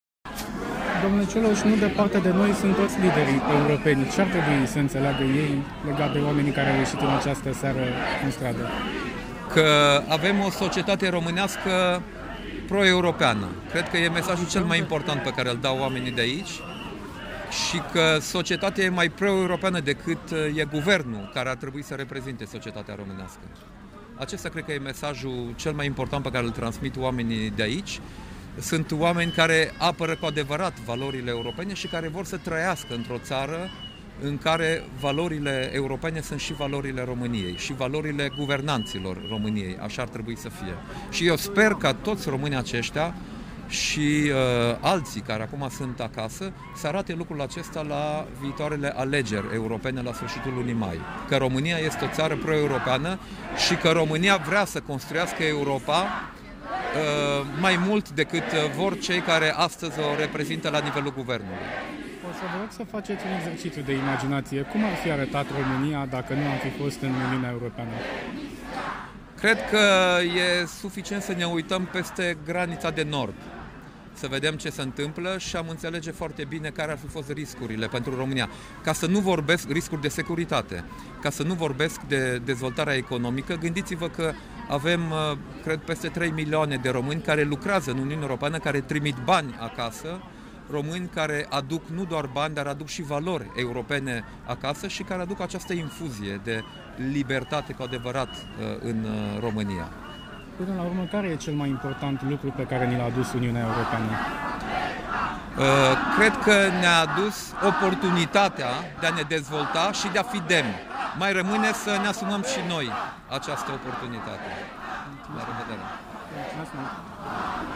Dacian-Ciolos-la-protestul-PRO-EUROPA-din-Piata-Palatului.mp3